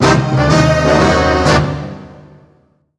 CTFPickUpFlag.wav